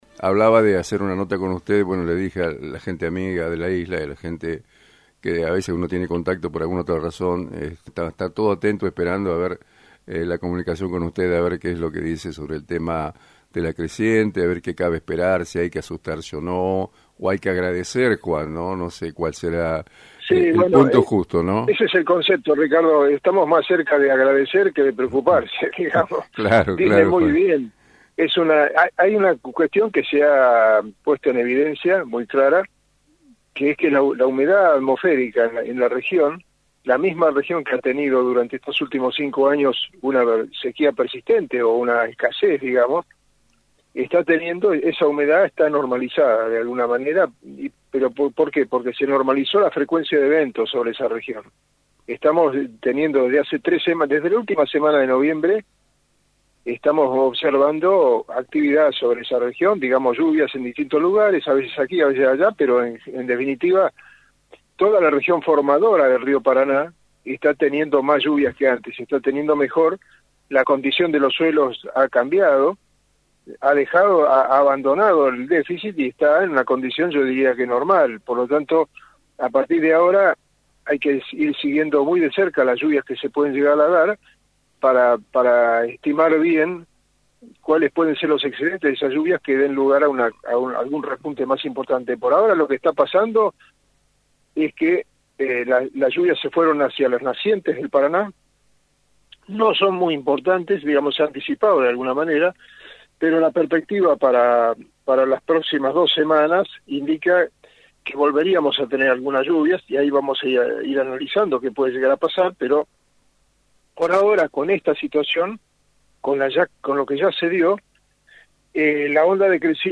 En diálogo con el programa Victoria Rural, de AM 980